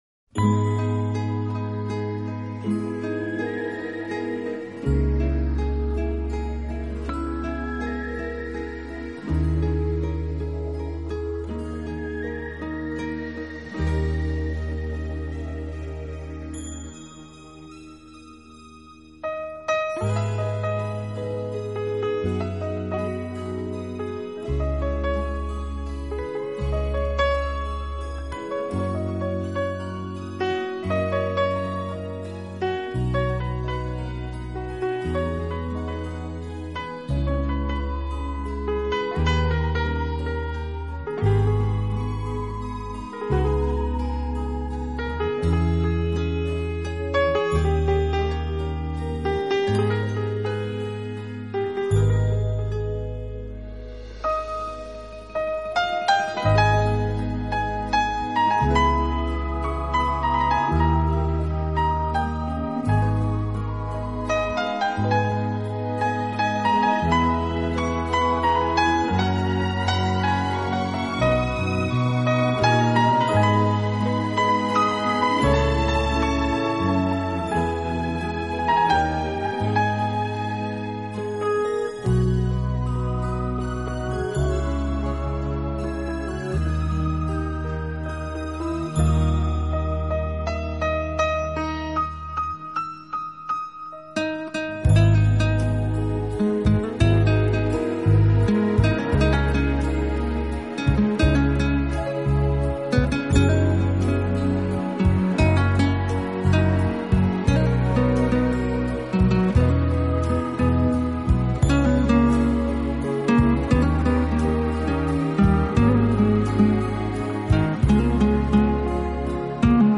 【轻音乐】
是乐队演奏的主要乐器，配以轻盈的打击乐，使浪漫气息更加浓厚。